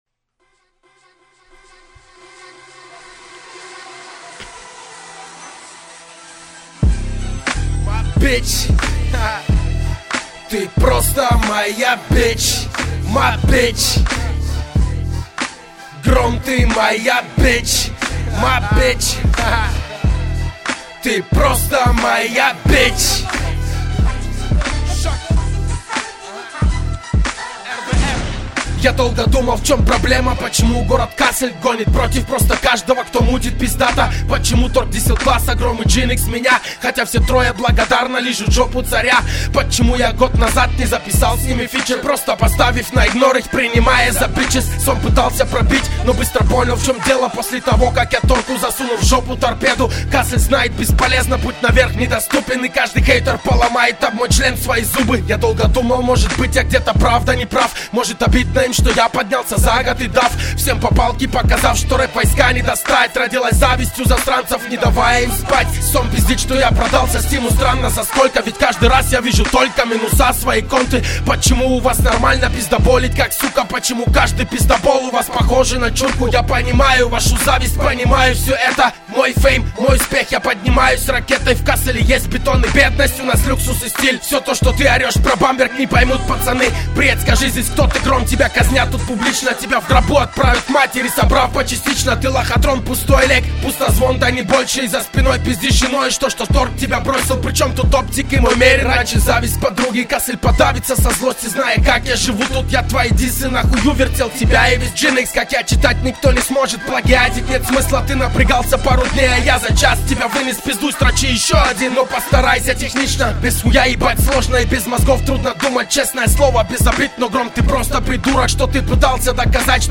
Категория: Русский Rap